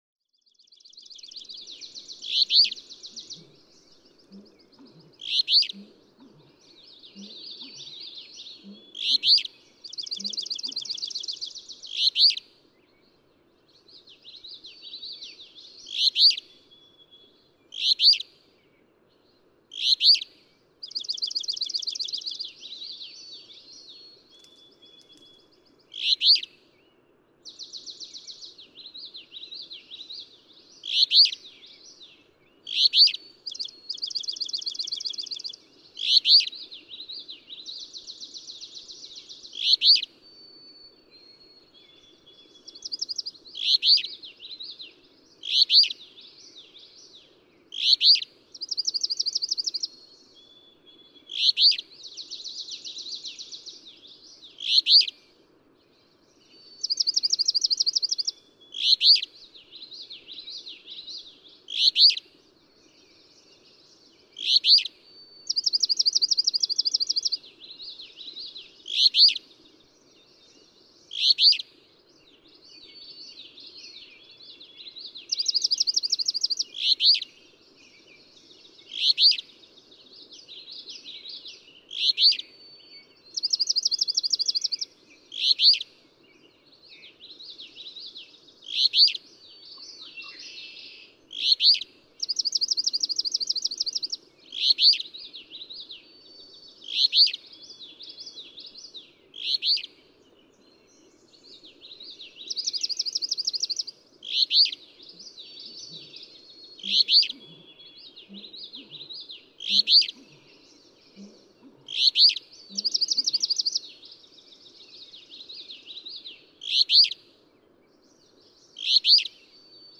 Alder flycatcher. American bittern in background (e.g., 0:01).
Berkshire Mountains, Heath, Massachusetts.
632_Alder_Flycatcher.mp3